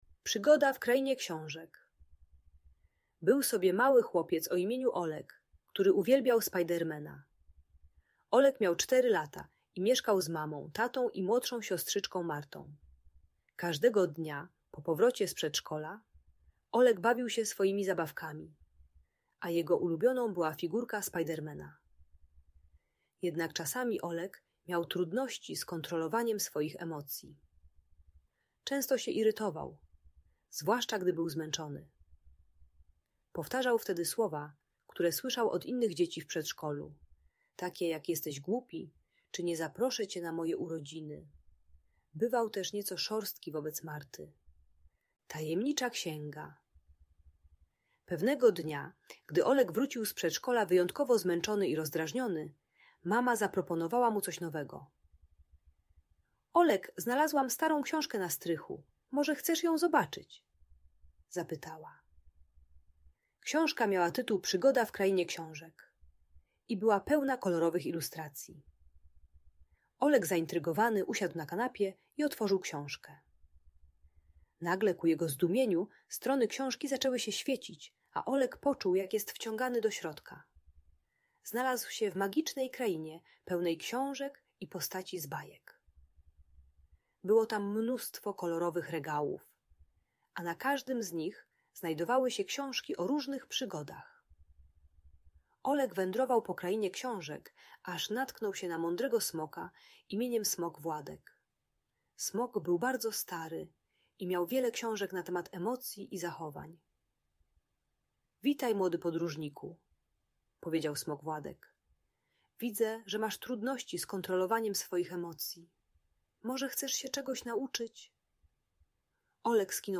Uczy techniki głębokiego oddychania, nazywania emocji ("jestem zmęczony") oraz przepraszania. Audiobajka o radzeniu sobie ze złością i byciem miłym dla rodzeństwa.